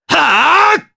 Dimael-Vox_Attack4_jp.wav